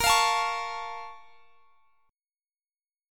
BbmM7 Chord
Listen to BbmM7 strummed